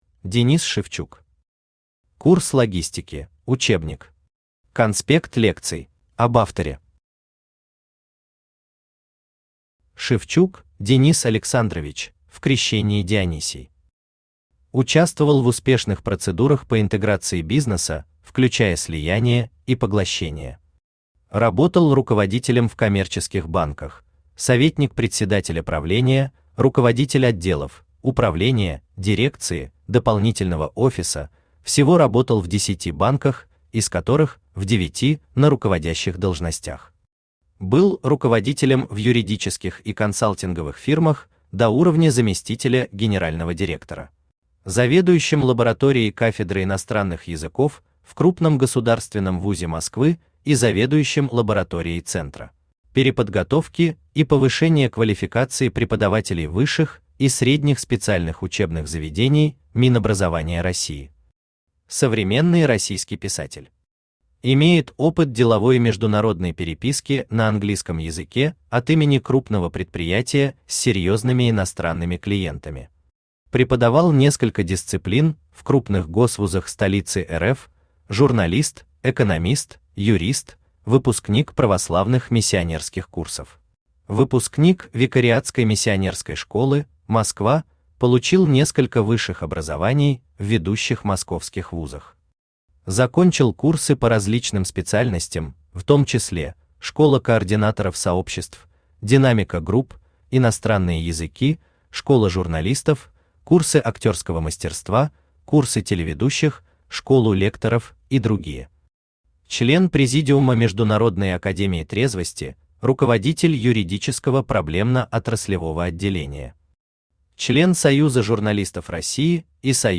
Аудиокнига Курс логистики: учебник. Конспект лекций | Библиотека аудиокниг
Конспект лекций Автор Денис Александрович Шевчук Читает аудиокнигу Авточтец ЛитРес.